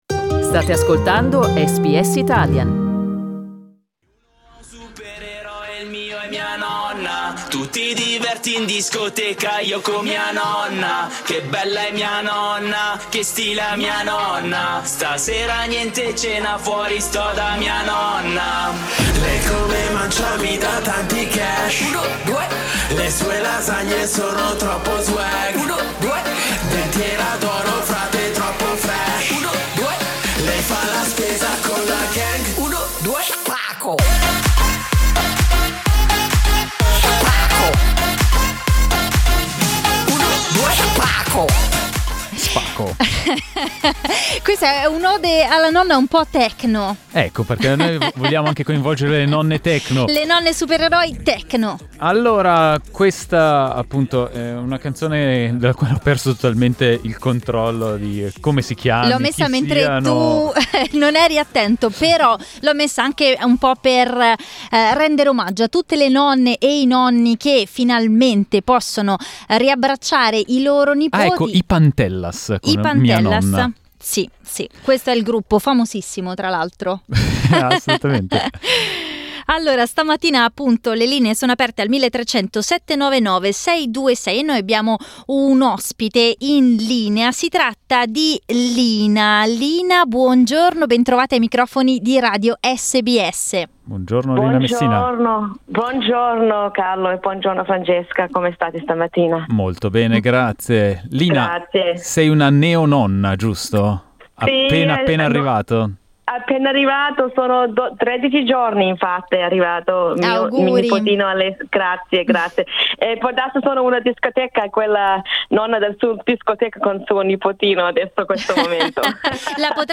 Today we talked to our listeners about the relationship between grandparents and grandchildren and in particular about how these two months passed and about the emotions experienced when it was finally possible to meet again.